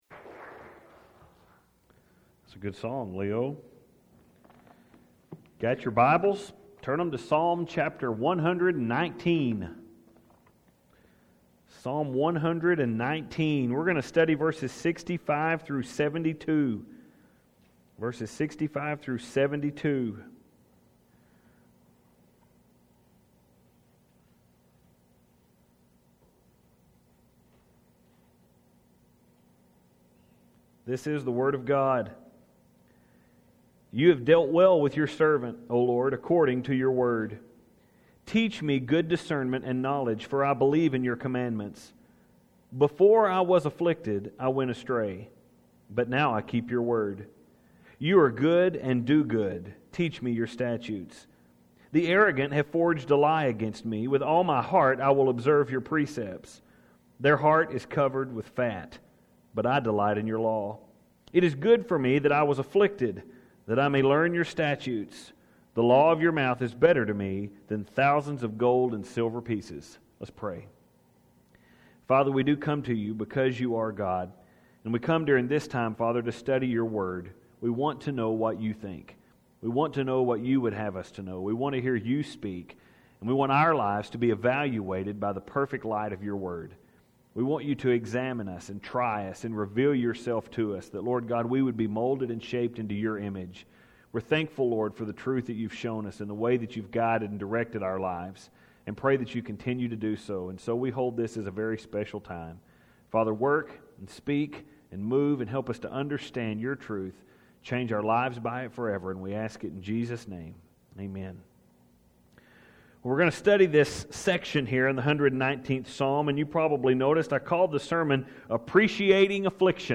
Rather, this is a sermon Meant to help us look at affliction differently.